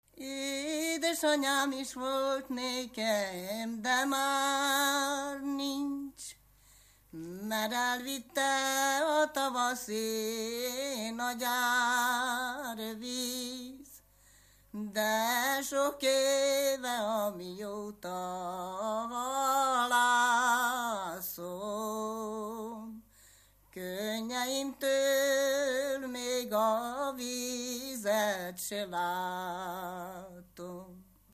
Erdély - Kolozs vm. - Sárvásár
ének
Stílus: 4. Sirató stílusú dallamok
Kadencia: 5 (4) 5 1